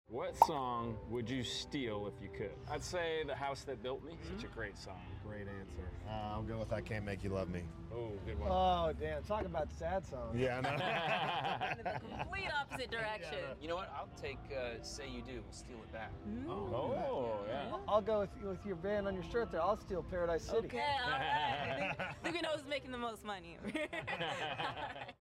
Old Dominion on what song they could steal if they could 👀 Watch the FULL interview on MUCH YT!